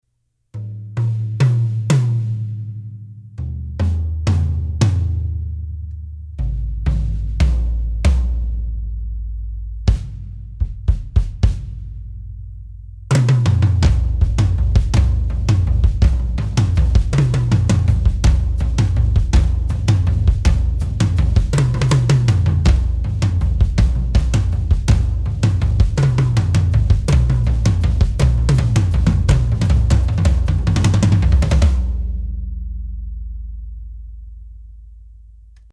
The result is a very strong and stable shell with a sharp attack, high sensitivity, and a wide tuning range. Ludwig describes Classic Maple drums as being ideal for large-scale touring and high-volume performances, featuring a big and open tone, extended resonance, and midrange projection.
The Classic Maple kit we were sent ($3,846) came with an 18×22 bass drum (no mount), 8×10 and 9×12 rack toms, and a 16×16 floor tom.
The six-lug toms have triple-flange steel hoops and Ludwig WeatherMaster Heavy clear batter heads with Medium clear bottoms.
PCU_Ludwig-Classic-maple-kit.mp3